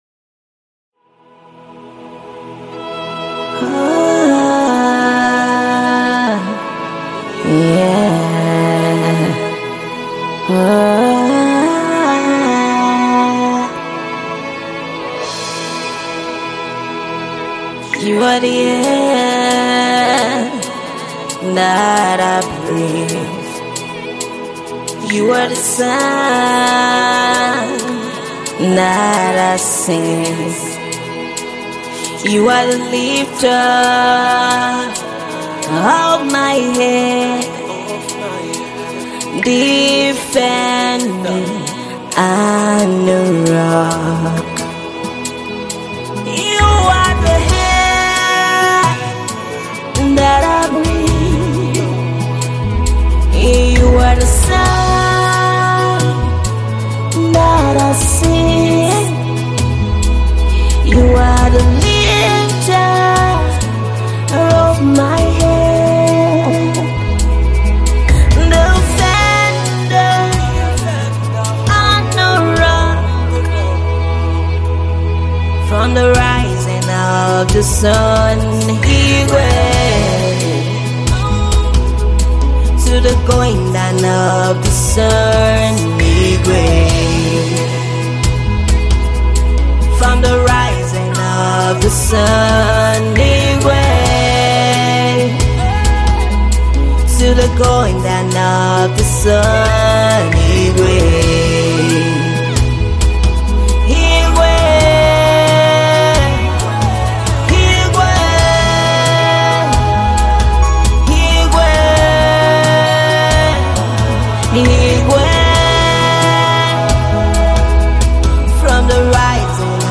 Versatile gospel artist
its a song to groove the Xmas season